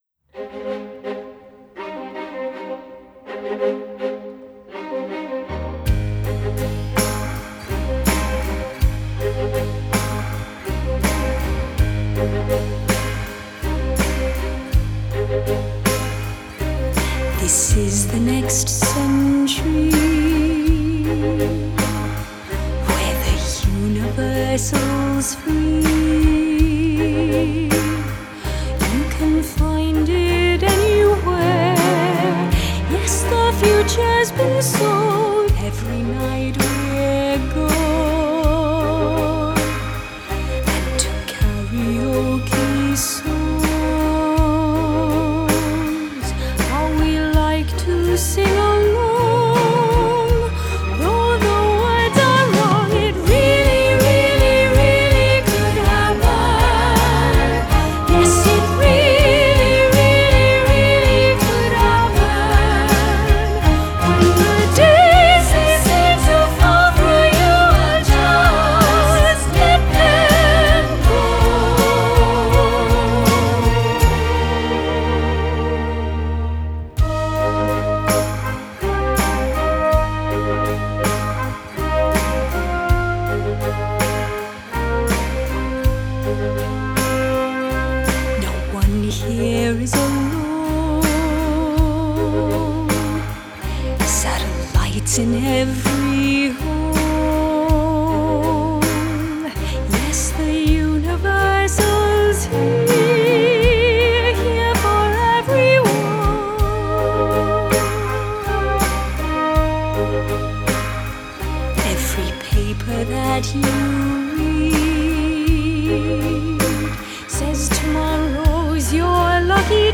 Genre: Classical Crossover, Classical